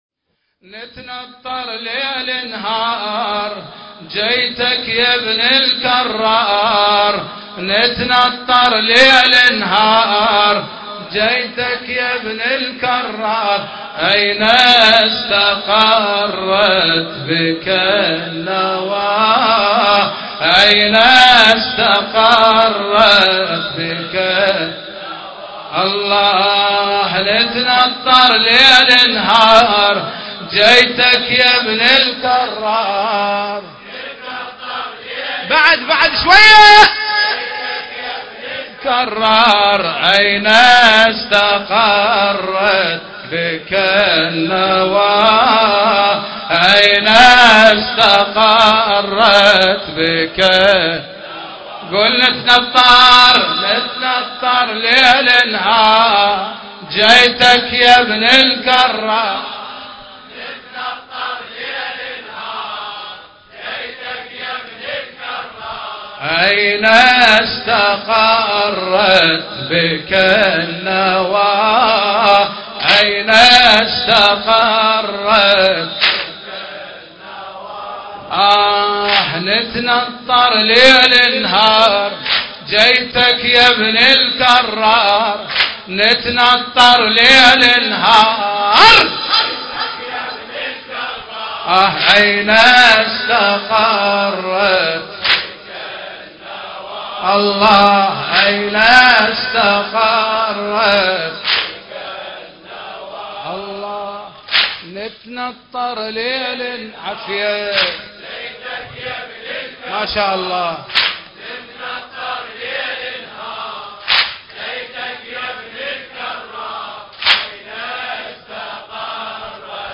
الحجم: 5.10 MB ليلة 29 شوال 1437 هـ عزاء شباب كربلاء المقدسة